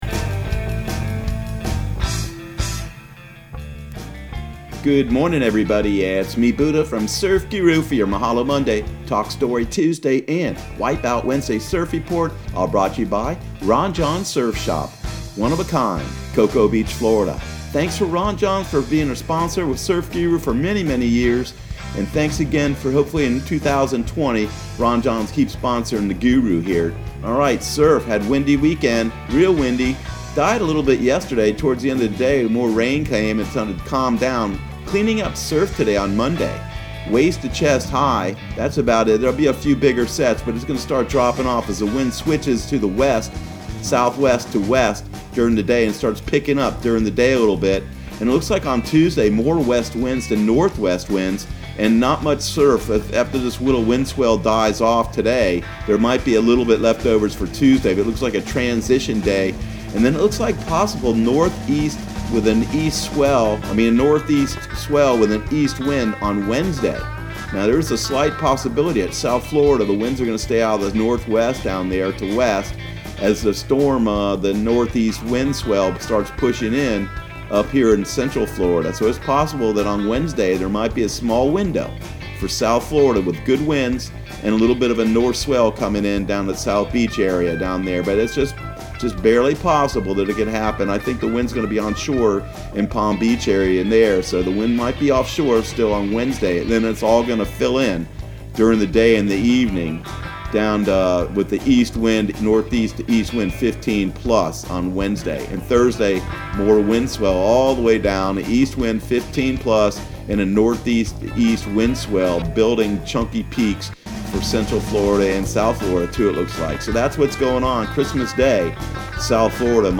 Surf Guru Surf Report and Forecast 12/23/2019 Audio surf report and surf forecast on December 23 for Central Florida and the Southeast.